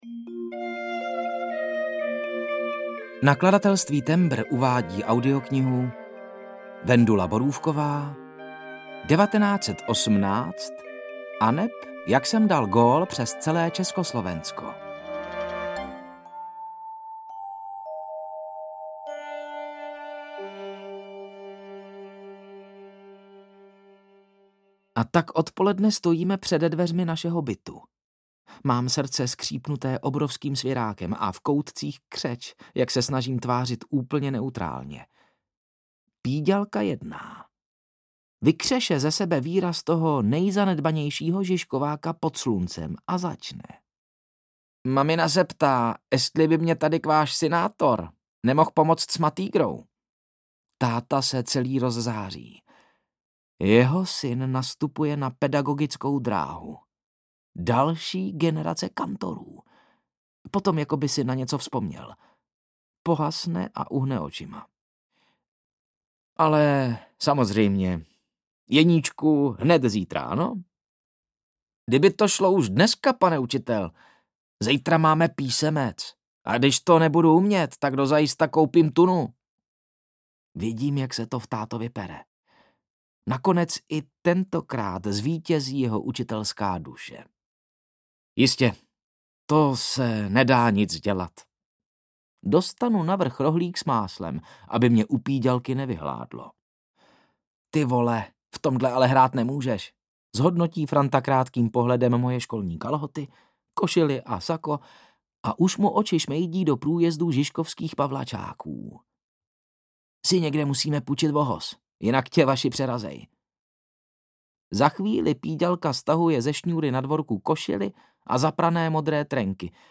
1918 aneb Jak jsem dal gól přes celé Československo audiokniha
Ukázka z knihy